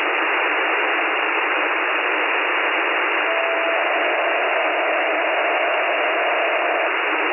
GoesDCS300bps.mp3